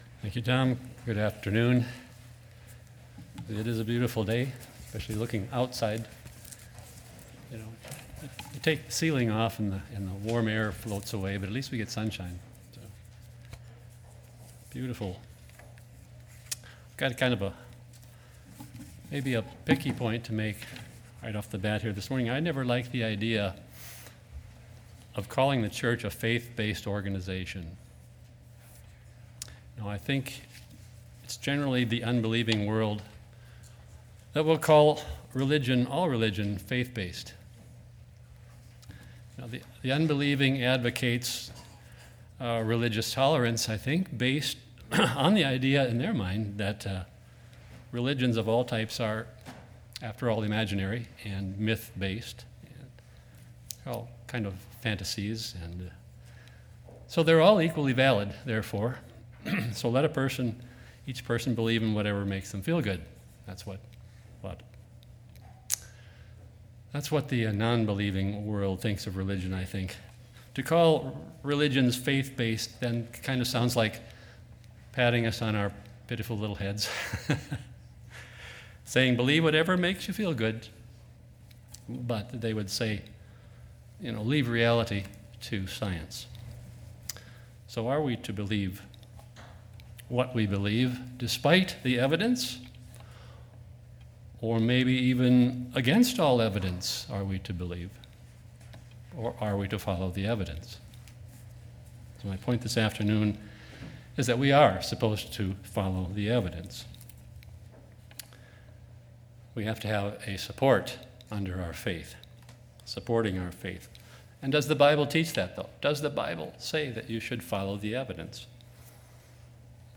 Is the church a "faith-based organization"? Is our faith based on faith itself? This message proposes that, to the contrary, our faith is based on foundations of evidence.
Sermons